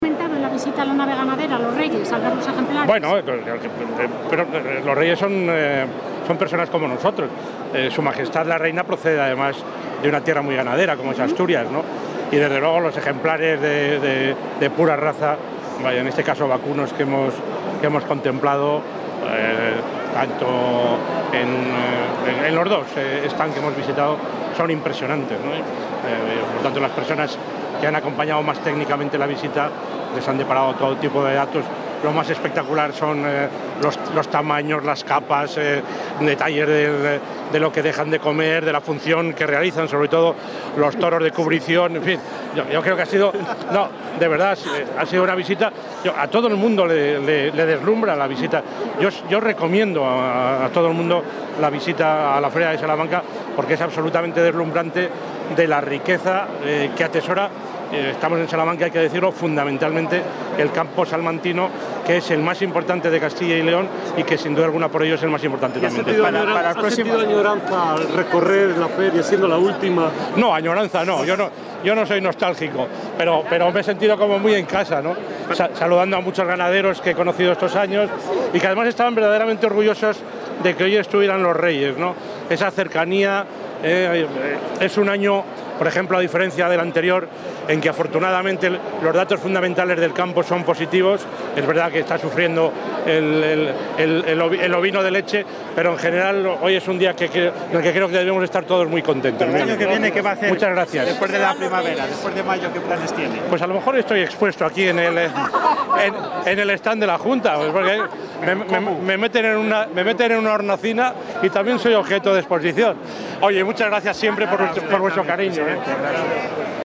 Declaraciones del presidente de la Junta.
El presidente de la Junta de Castilla y León, Juan Vicente Herrera, ha visitado Salamaq 2018 durante el acto de inauguración por parte de SS.MM. los Reyes.